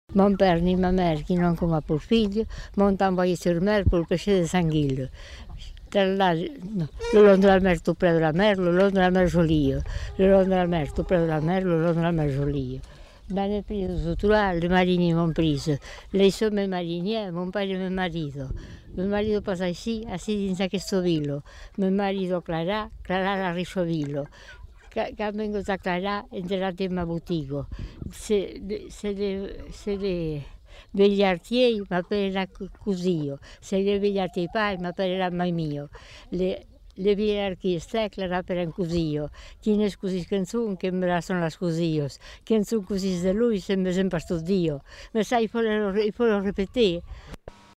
Aire culturelle : Savès
Genre : chant
Effectif : 1
Type de voix : voix de femme
Production du son : récité